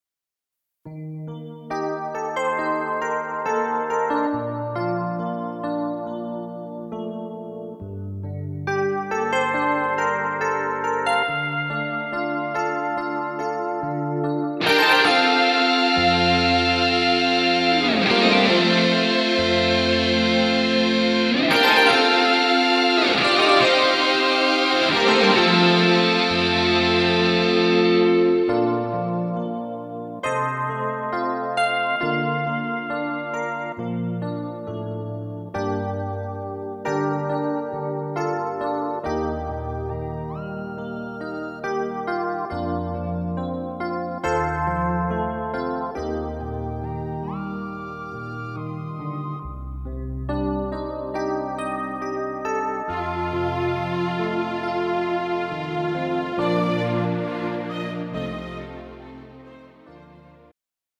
음정 Bb Key
장르 pop 구분